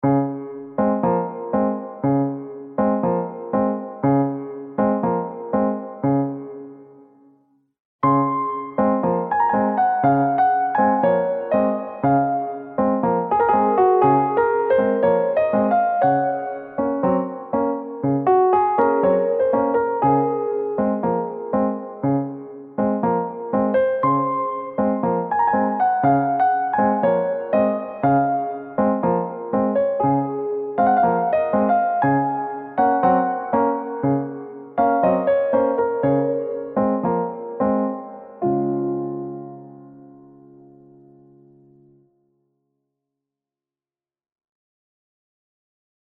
日差し、ほのぼの、のんびり